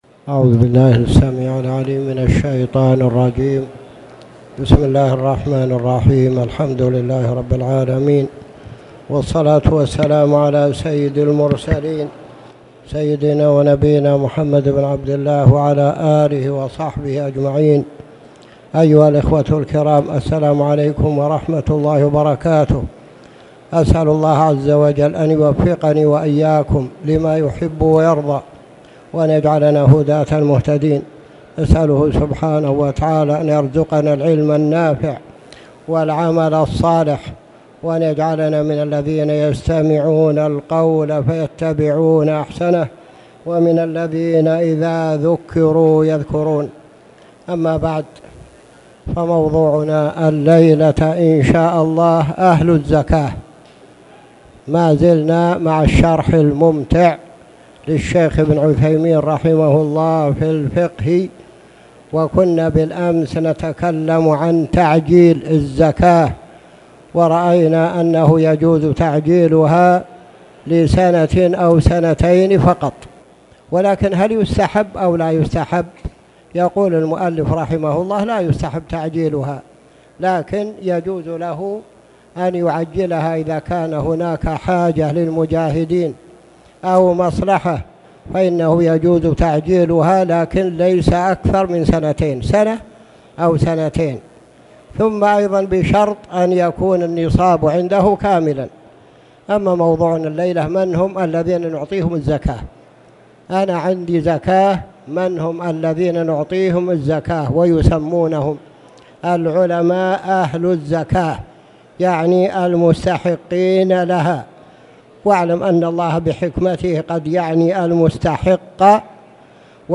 تاريخ النشر ٨ رجب ١٤٣٨ هـ المكان: المسجد الحرام الشيخ